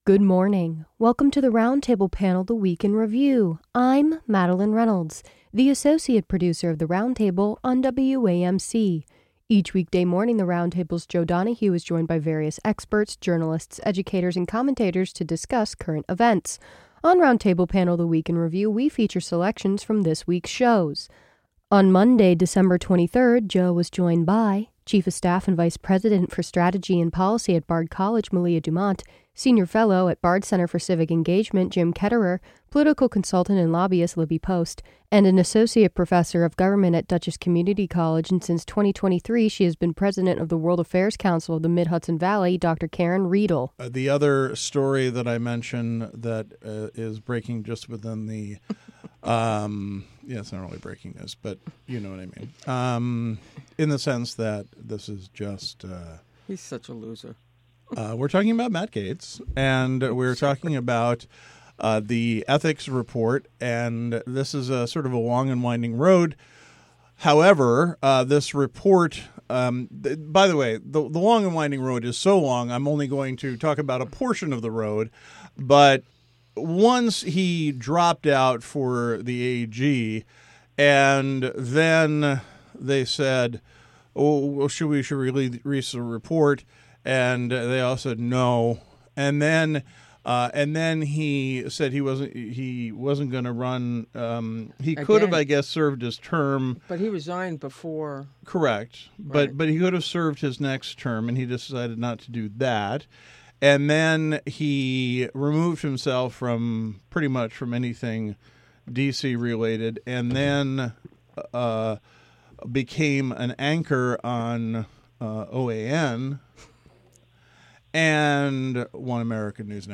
WAMC's The Roundtable is an award-winning, nationally recognized eclectic talk program. The show airs from 9 a.m. to noon each weekday and features news, interviews, in-depth discussion, music, theatre, and more!